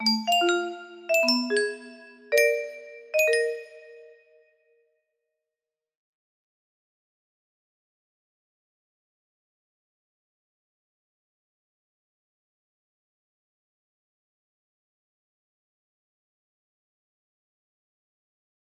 Test music box melody